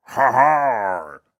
Minecraft Version Minecraft Version snapshot Latest Release | Latest Snapshot snapshot / assets / minecraft / sounds / mob / pillager / celebrate3.ogg Compare With Compare With Latest Release | Latest Snapshot
celebrate3.ogg